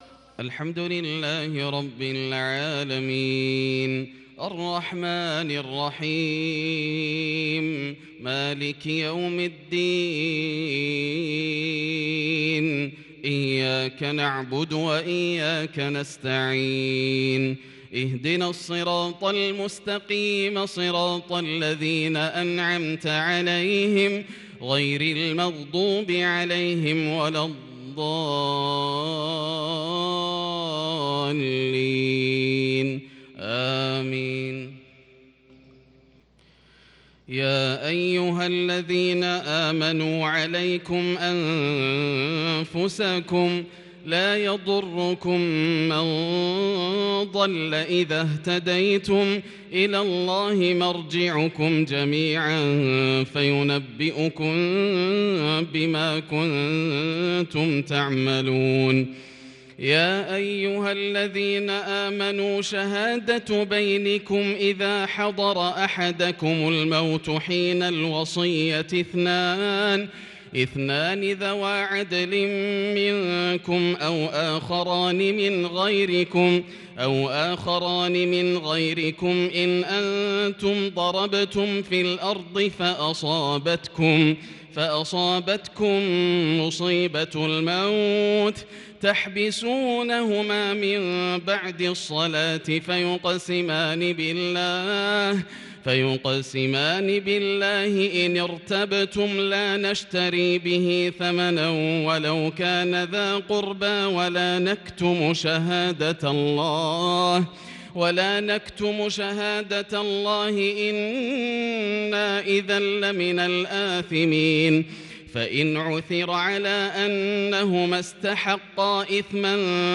عشاء السبت 1-7-1442هـ من سورة المائدة | Isha prayer from Surat AlMa'idah 13/2/2021 > 1442 🕋 > الفروض - تلاوات الحرمين